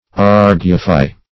Argufy \Ar"gu*fy\, v. t. & i. [Argue + -fy.]